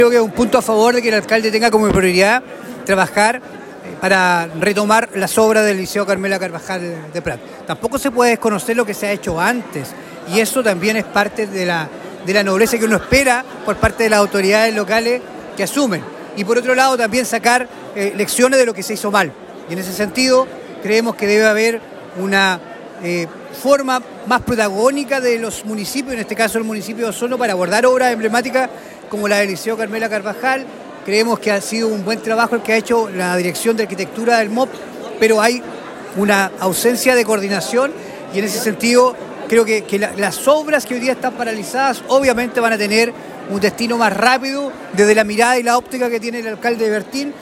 El pasado viernes, diversas autoridades nacionales y regionales participaron en la ceremonia de instalación del nuevo gobierno comunal de Osorno, encabezado por el Alcalde Jaime Bertín y su Concejo Municipal.